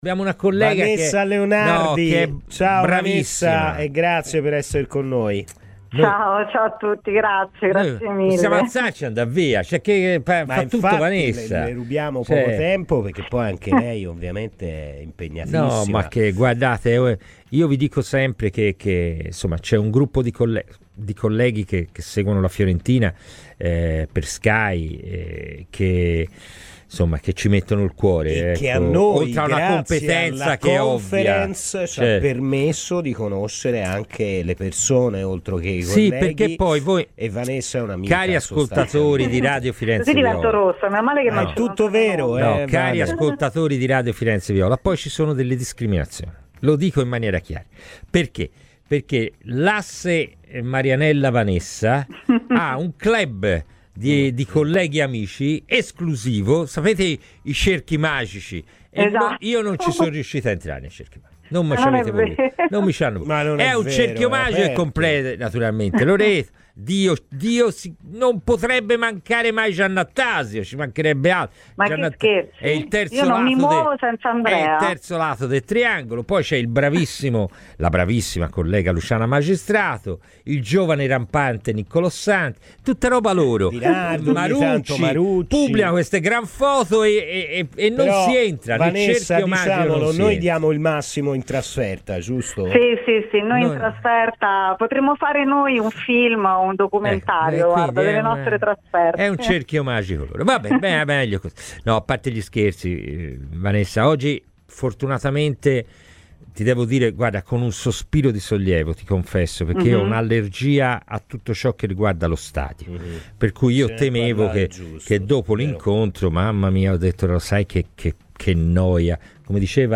La giornalista di Sky Sport